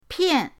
pian4.mp3